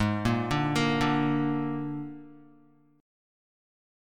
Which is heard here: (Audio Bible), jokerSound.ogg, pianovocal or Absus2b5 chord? Absus2b5 chord